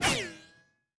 extraspeed.wav